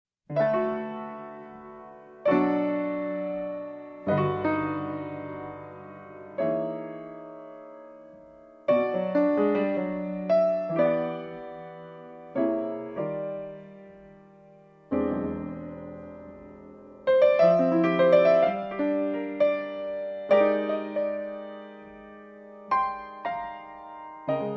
Prophet V, Polymoog, Drums, Finger Bells and Voice